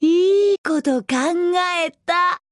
Voice Actor Yuko Sanpei
Menu Voice Lines